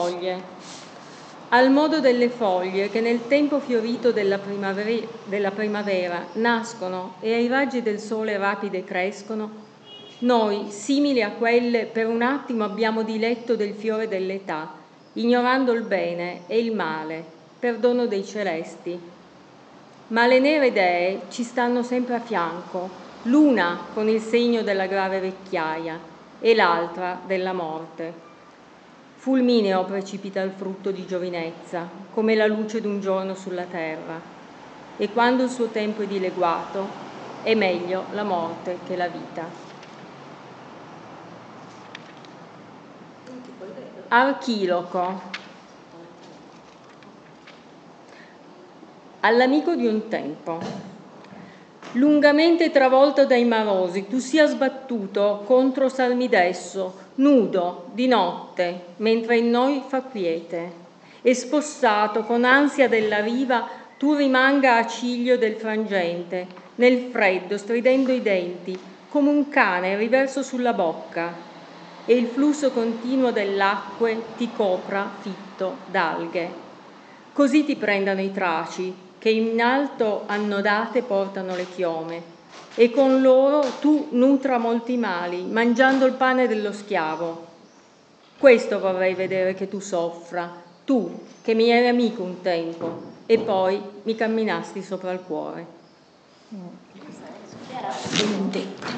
Siamo arrivati al penultimo incontro di lettura ad alta voce.